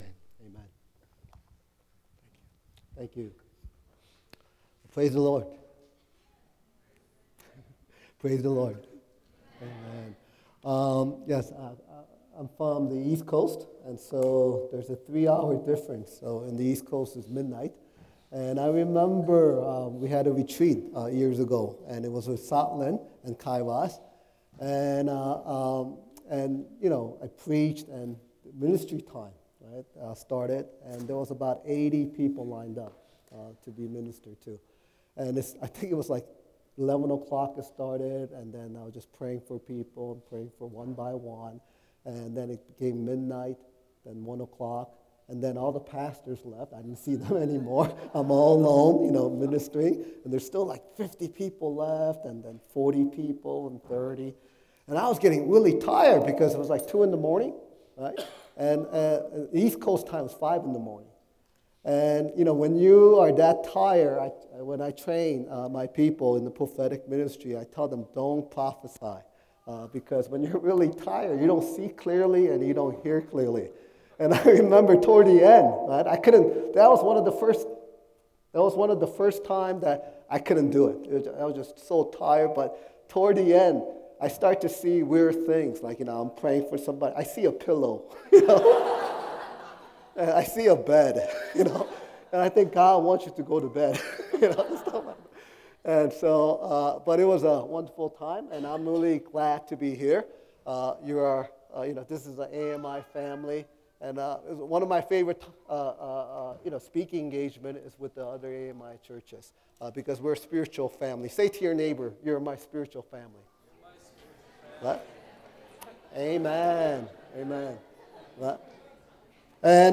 2014 Retreat - Breakthrough